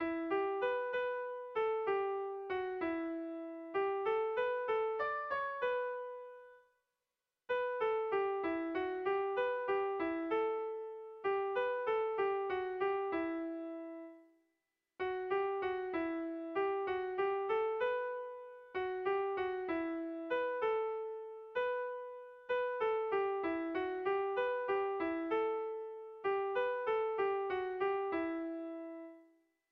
Kontakizunezkoa
Zortziko txikia (hg) / Lau puntuko txikia (ip)
ABDB